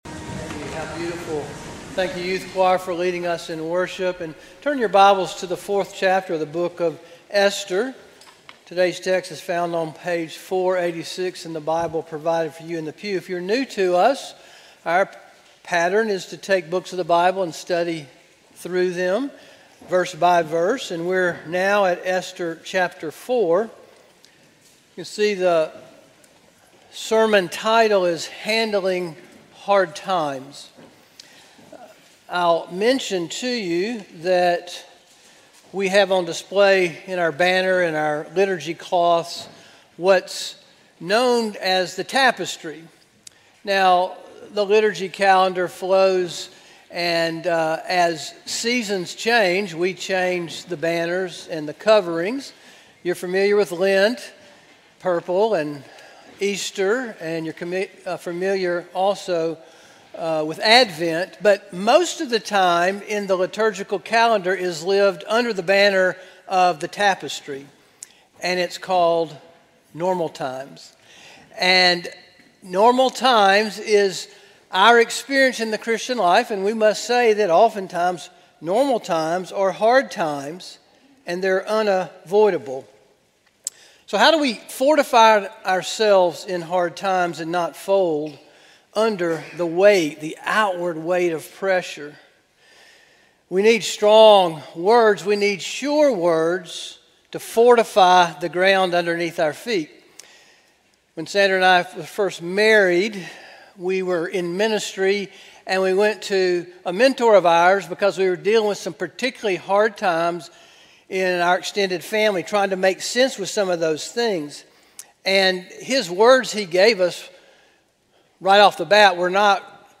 A sermon from the series "Esther." Esther 6:5-7:10 November 2, 2025 Morning